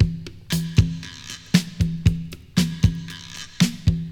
• 117 Bpm Drum Loop Sample E Key.wav
Free drum loop - kick tuned to the E note. Loudest frequency: 1291Hz
117-bpm-drum-loop-sample-e-key-OEG.wav